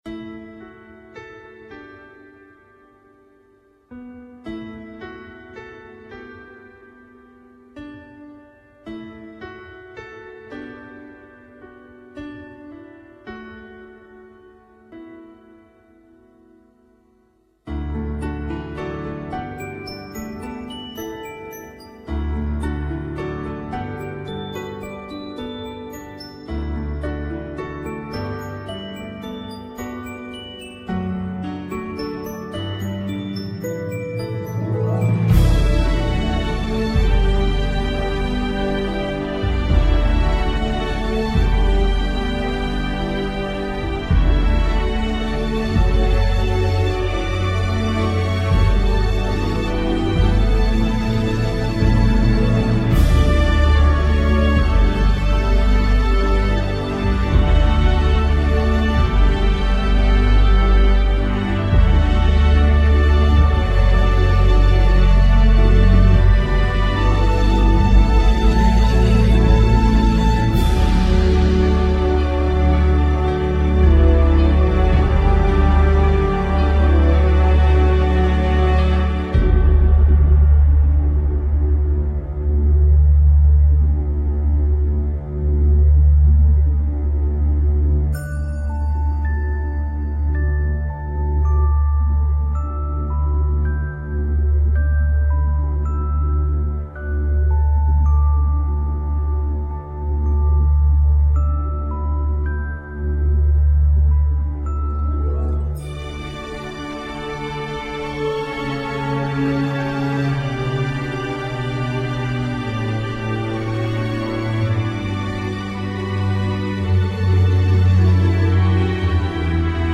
Une magnifique musique symphonique